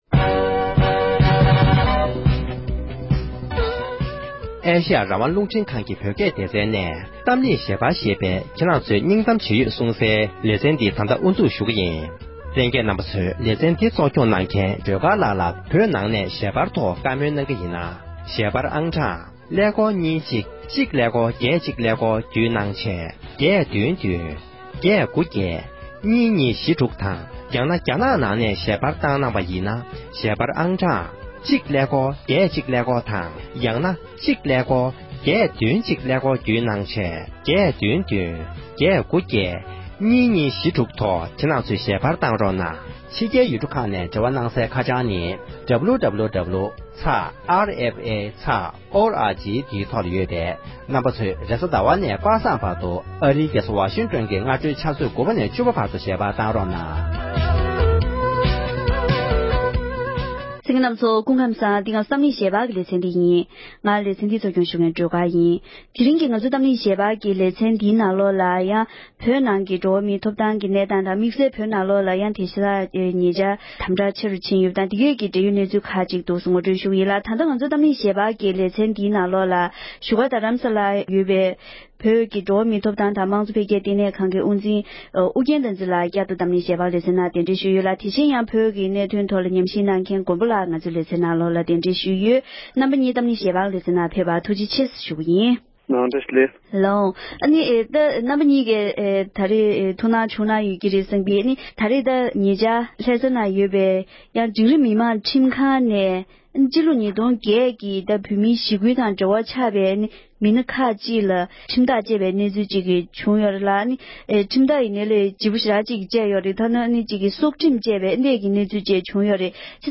གཏམ་གླེང་ཞལ་པར་